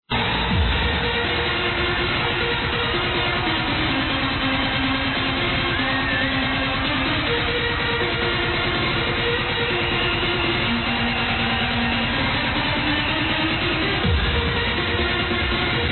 Dunno Trance badboy from 1999/2000
It sounds fairly commercial so it shouldn't be too hard.